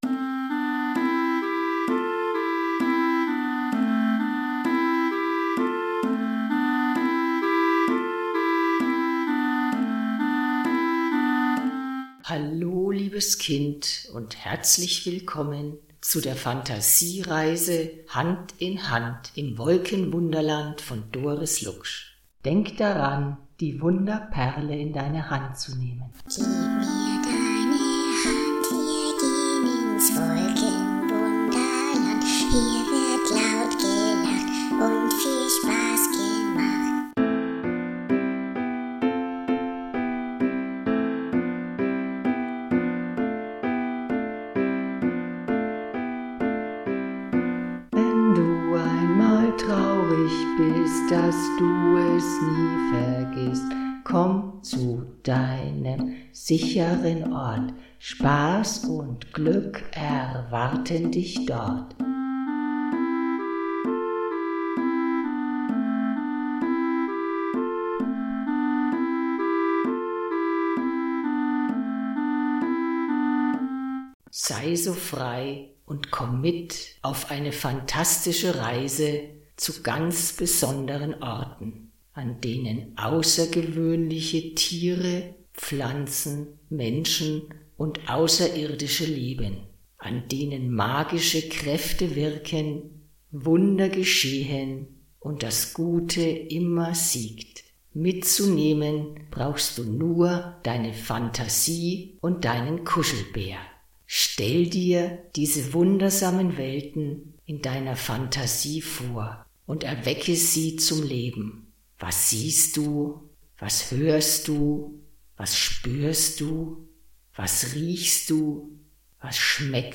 mit dem vorgelesenen Buchtext und dem Wolkenwunderlied von Dipl.-Psych.